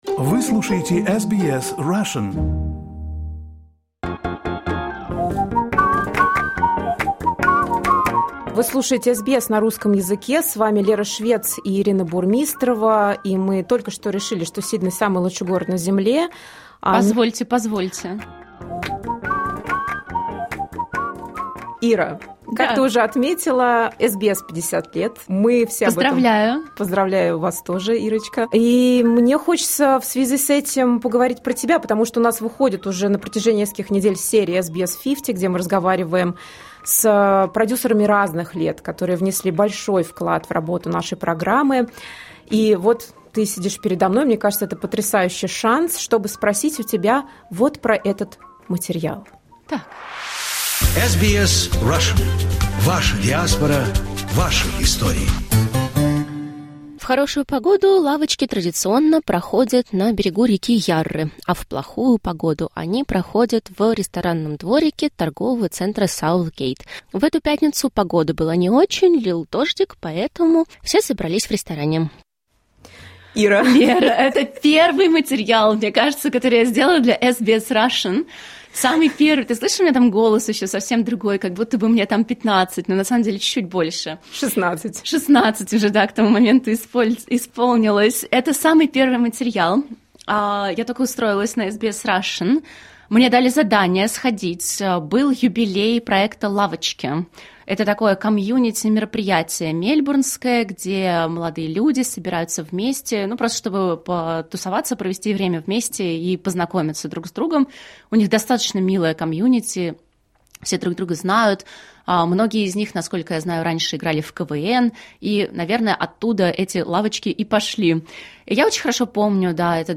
В июне 2025 года австралийской государственной теле- и радиовещательной компании SBS исполнилось 50 лет. В последнем эпизоде серии ведущие русскоязычной службы делятся своей историей.